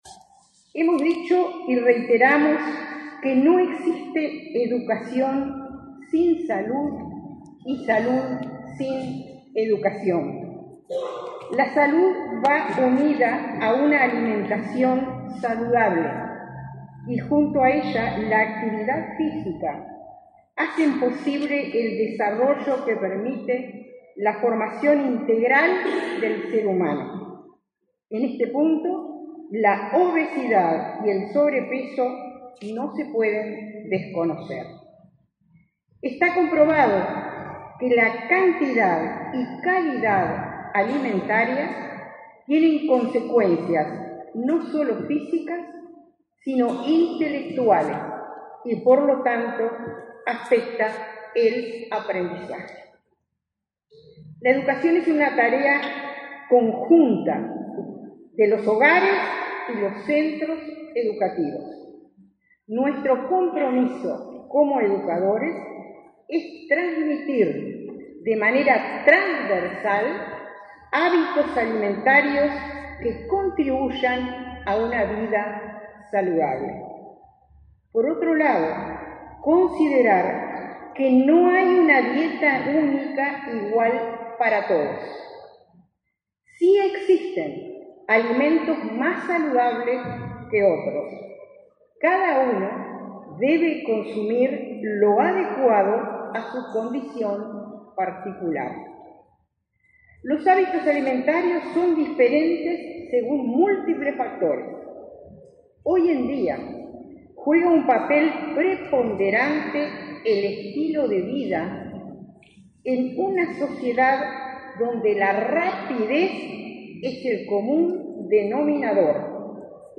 Palabras de autoridades en acto de ANEP
Palabras de autoridades en acto de ANEP 16/07/2024 Compartir Facebook X Copiar enlace WhatsApp LinkedIn La consejera de la Administración Nacional de Educación Pública (ANEP) Dora Graziano; el secretario nacional del Deporte, Sebastián Bauzá, y la ministra de Salud Pública, Karina Rando, participaron en la presentación de un curso de alimentación saludable y actividad física en entornos educativos. El evento fue realizado este martes 16 en Montevideo.